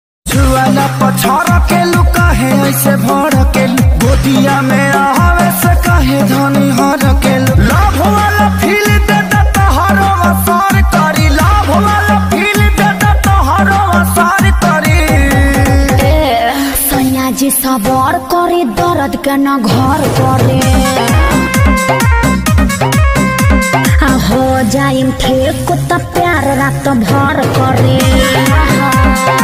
bhojpuri ringtone new